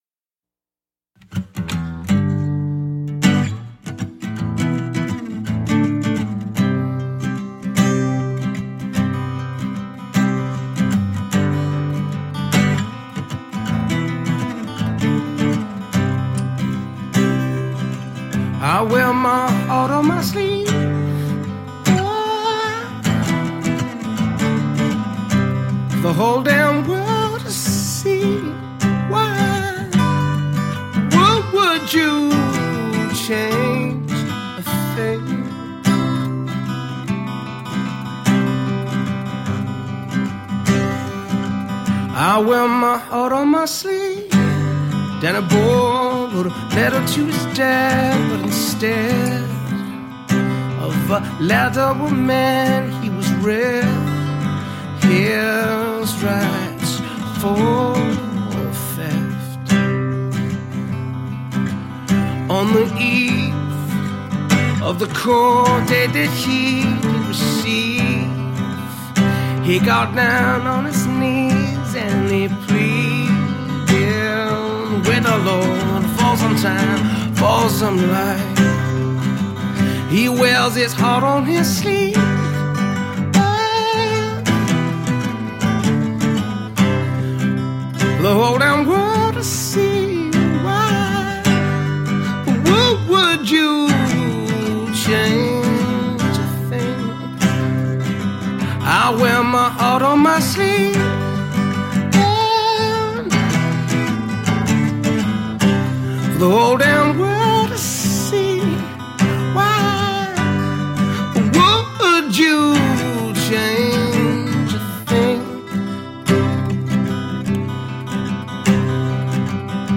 A melodic blend of folk and pop.
roots based, melodic, mix of singer songwriter goodness
Tagged as: Alt Rock, Folk-Rock, Classic rock